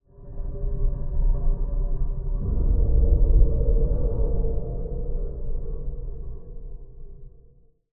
Commotion14.ogg